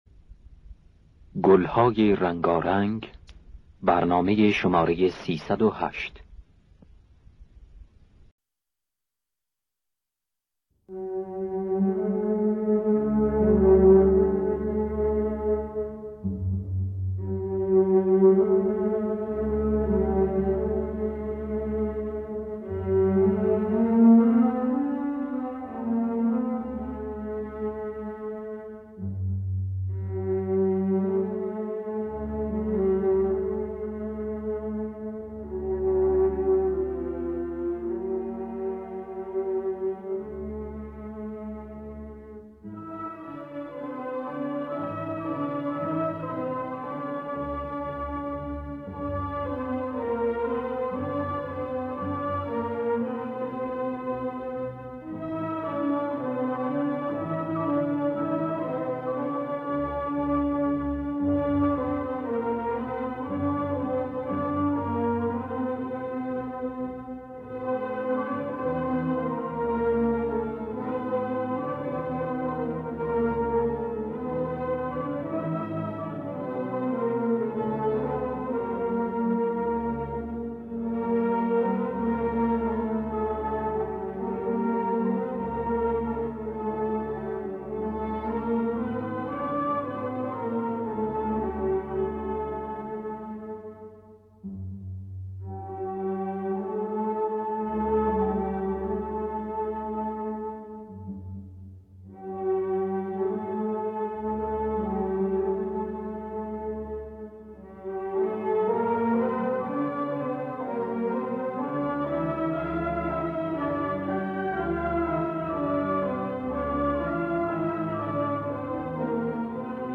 دانلود گلهای رنگارنگ ۳۰۸ با صدای عبدالوهاب شهیدی در دستگاه بیات ترک.
خوانندگان: عبدالوهاب شهیدی نوازندگان: پرویز یاحقی جواد معروفی